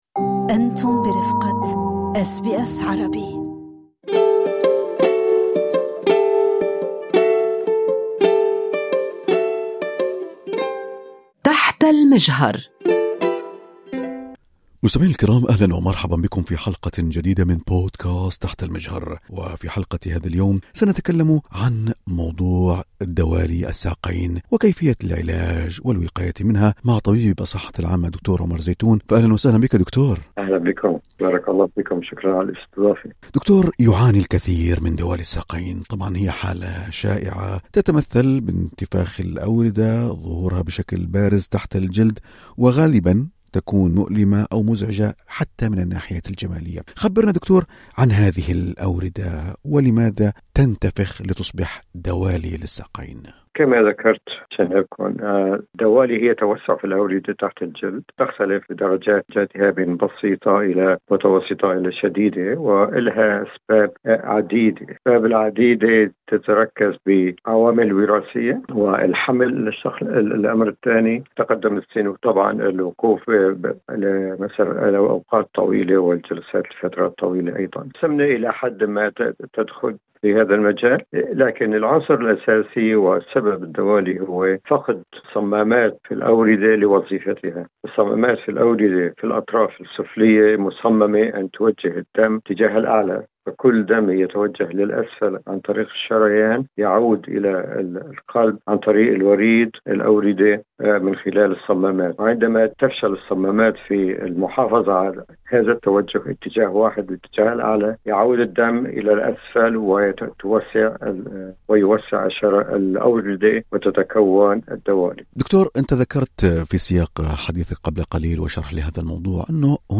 دوالي الساقين ليست مجرد عرَض، بل مؤشر على خلل داخلي في الجسم طبيب يشرح